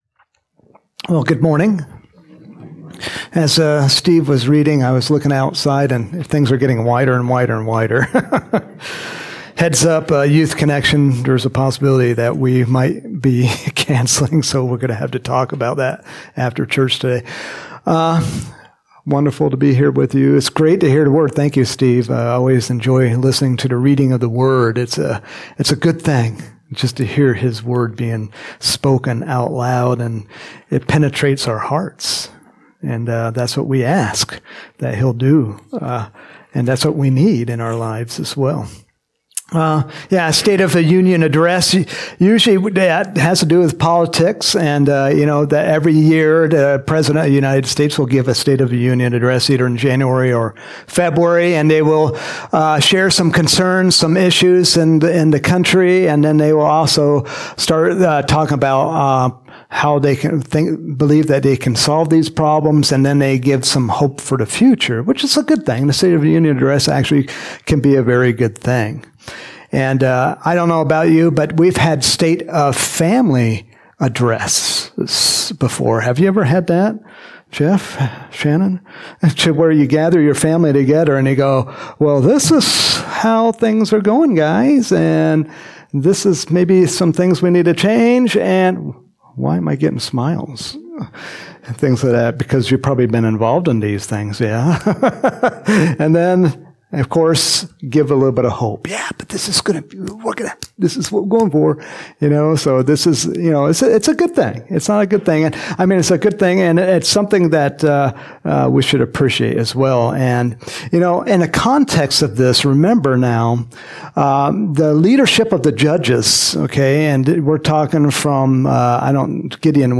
Home › Sermons › January 28, 2024